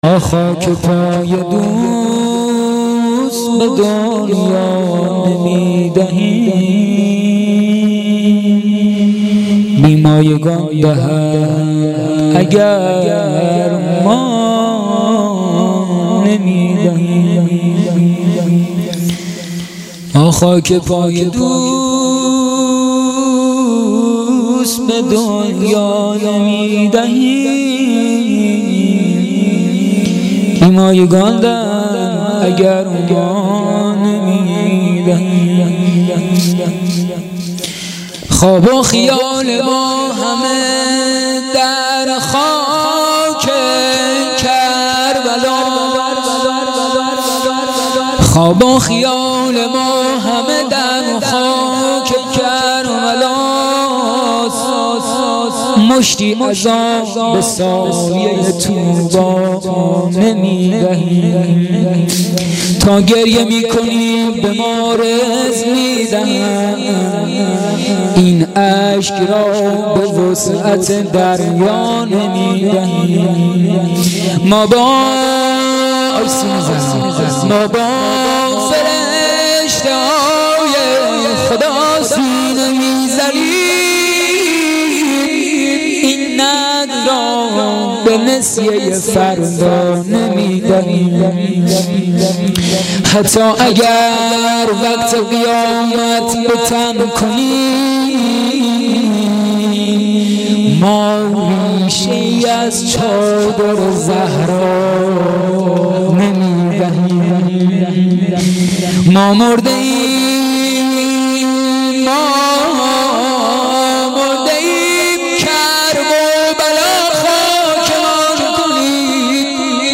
مداحی
به مناسبت ایام فاطمیه